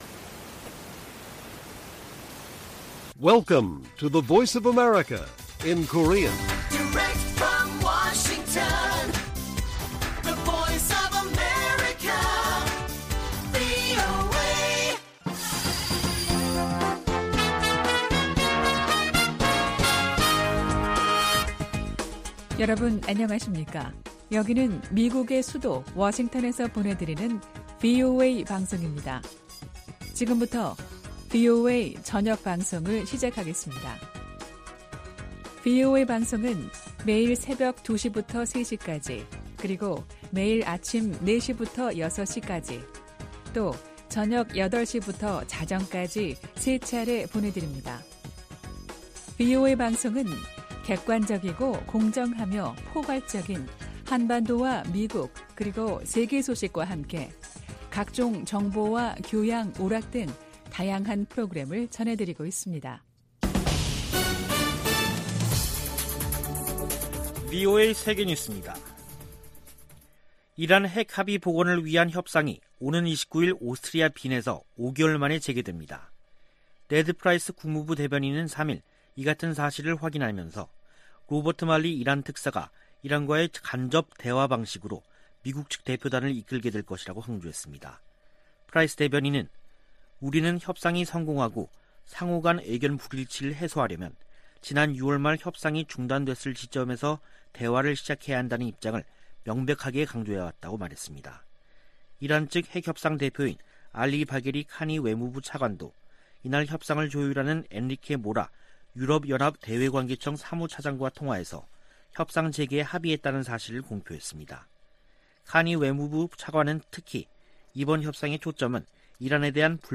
VOA 한국어 간판 뉴스 프로그램 '뉴스 투데이', 2021년 11월 4일 1부 방송입니다. 북한이 유엔총회에서 주한 유엔군사령부 해체를 다시 주장했습니다. 마크 밀리 미 합참의장은 북한 정부가 안정적이라며 우발적 사건이 발생하지 않을 것으로 내다봤습니다. 중국의 핵탄두가 2030년 1천개를 넘어설 수 있다고 미 국방부가 전망했습니다.